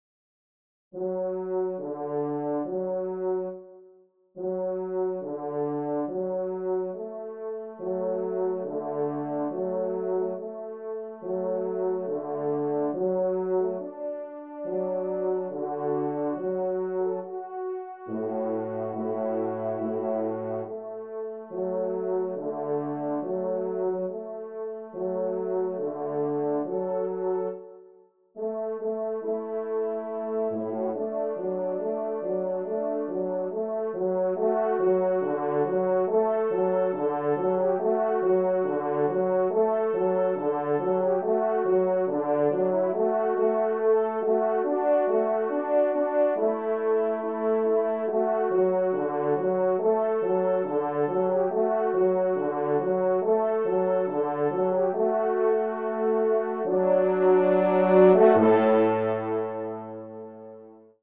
3ème Trompe